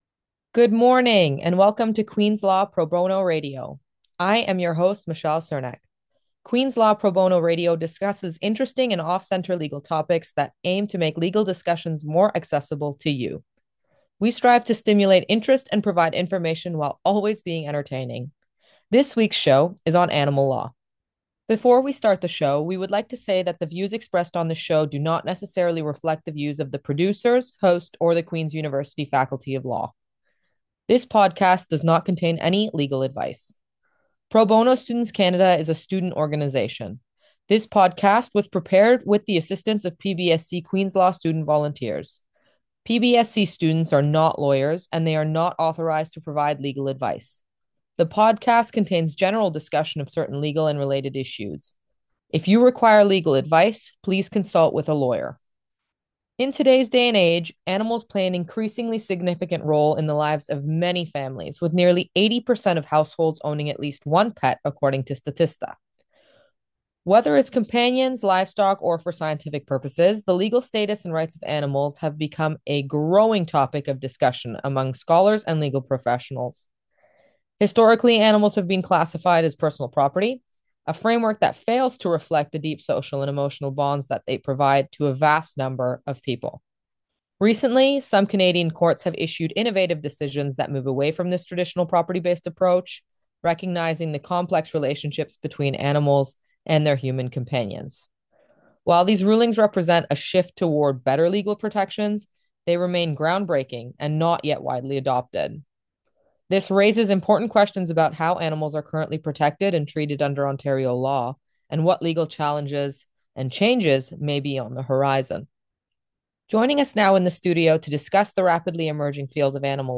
PBSC-interview-Beyond-Property.m4a